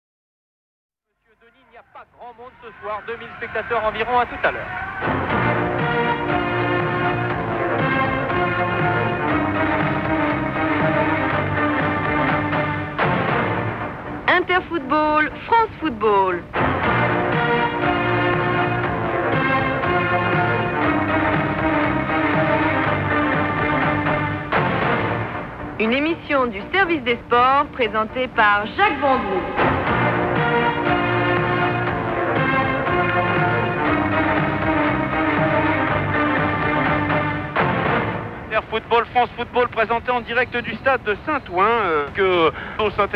Жанр: Pop, Alternative, Indie Pop, Synthpop, Dance-Pop